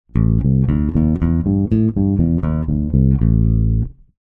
Escuchar la escala de blues tocada con bajo
Este audio corresponde a la escala de blues de A , asi que, es fantástico para hacerte una idea de como van a sonar las digitaciones que a continuación vamos a estudiar, que también parten desde la nota A.
escala-de-blues-menor.mp3